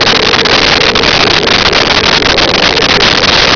Sfx Amb Wind Howling Loop
sfx_amb_wind_howling_loop.wav